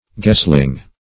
gesling - definition of gesling - synonyms, pronunciation, spelling from Free Dictionary Search Result for " gesling" : The Collaborative International Dictionary of English v.0.48: Gesling \Ges"ling\, n. A gosling.